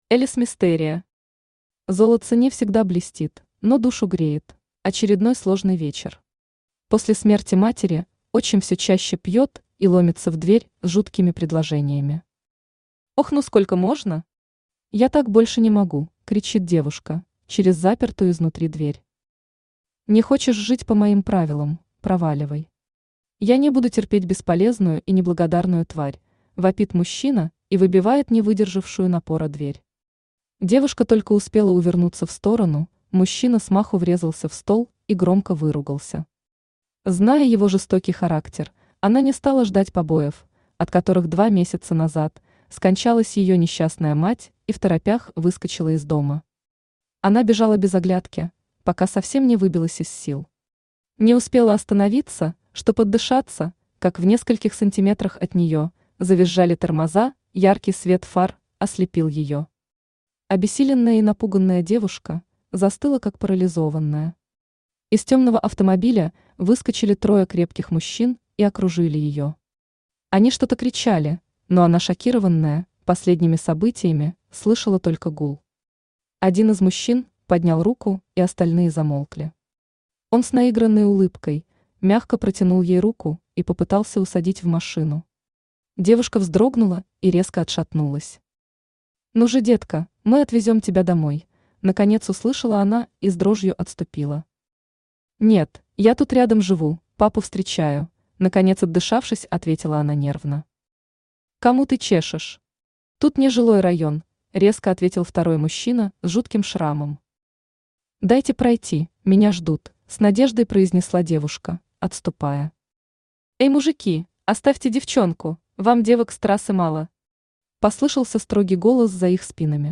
Автор Элис Мистерия Читает аудиокнигу Авточтец ЛитРес.